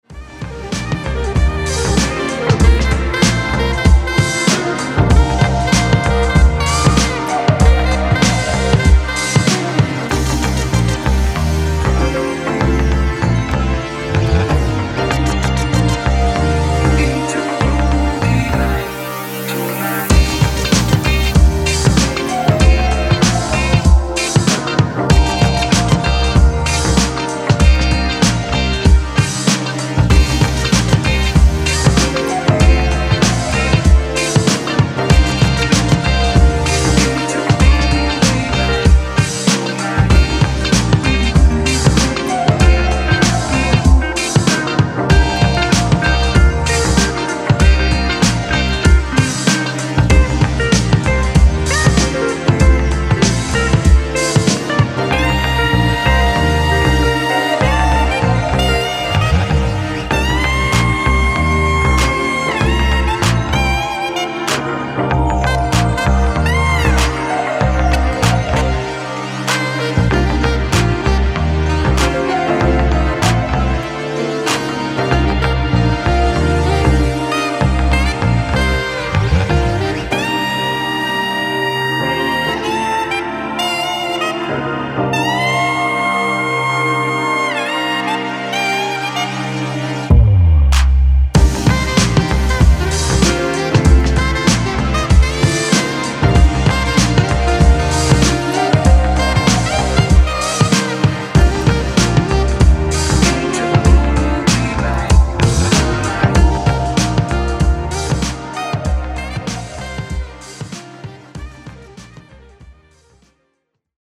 House Detroit